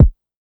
808 mafia kick.wav